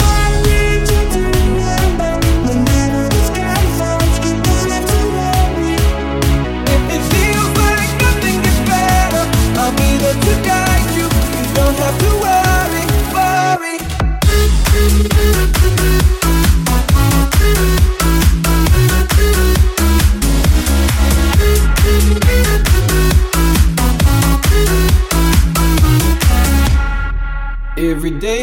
Genere: pop, deep, club, remix